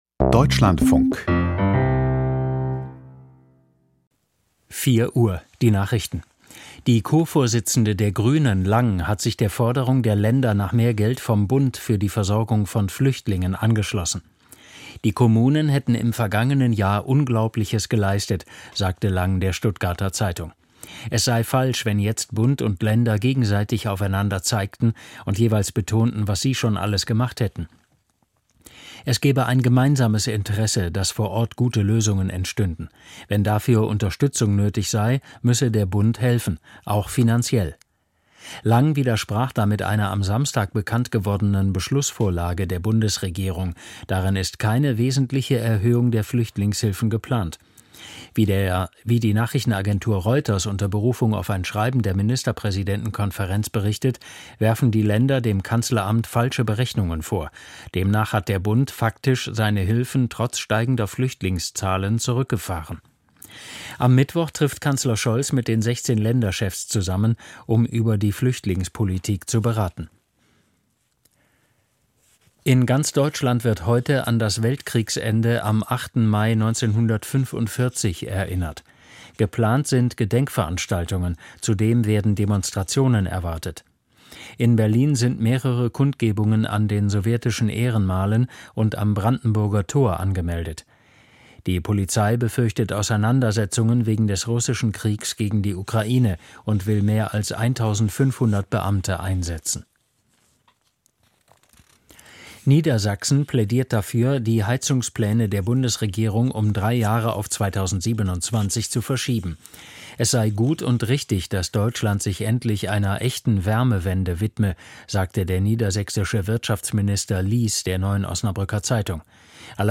Nachrichten vom 08.05.2023, 04:00 Uhr